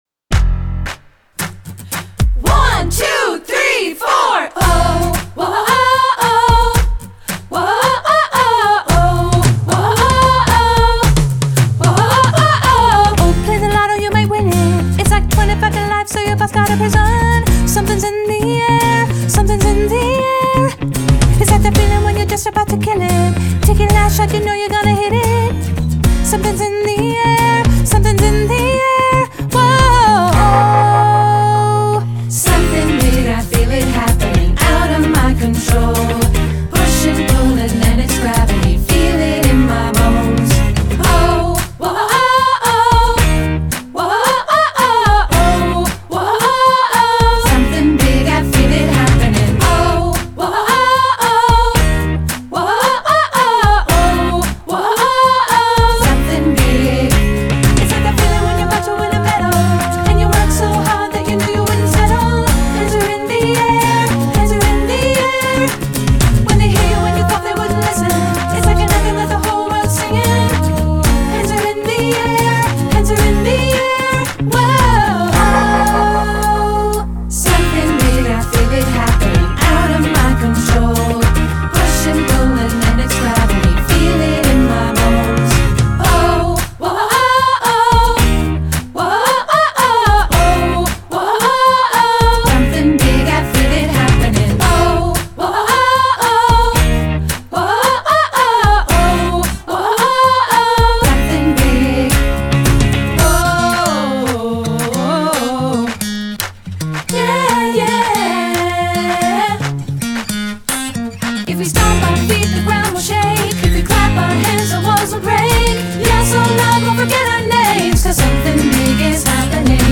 super energetic pop classic